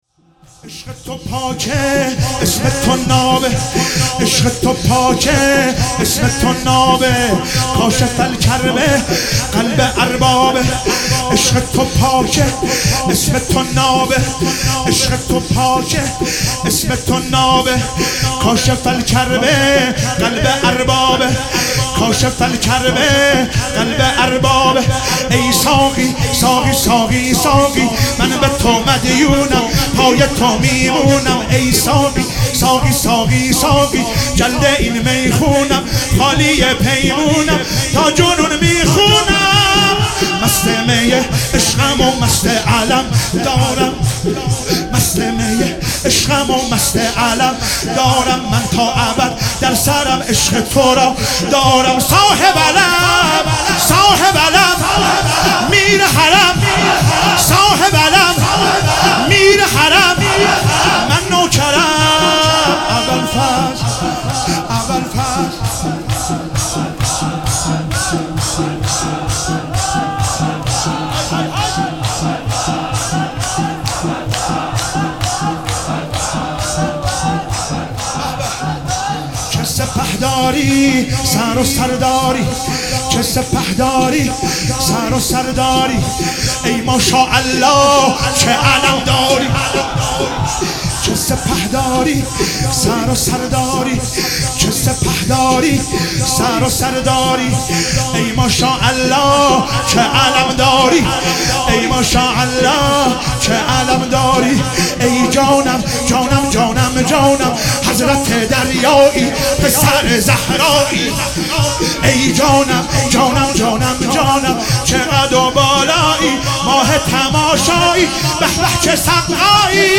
شب تاسوعا محرم96 - شور - عشق تو پاک اسم نابه